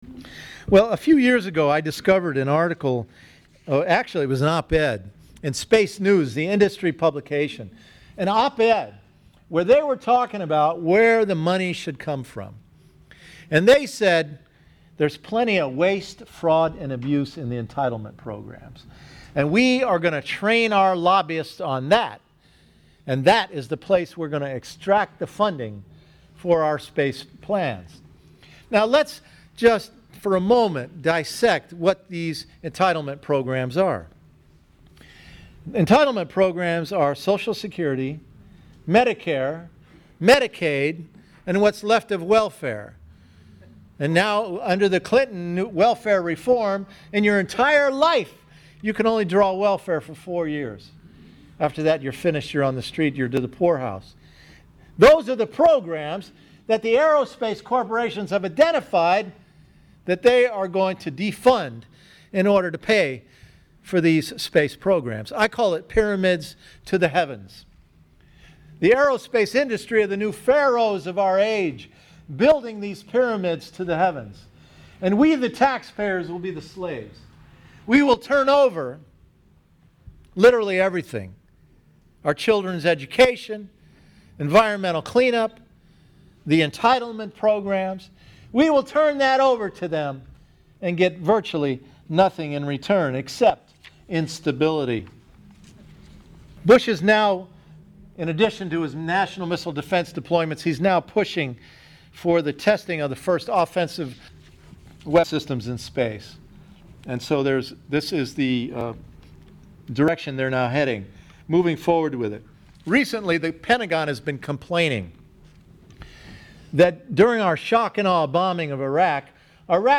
spoke at the Unitarian Universalist hall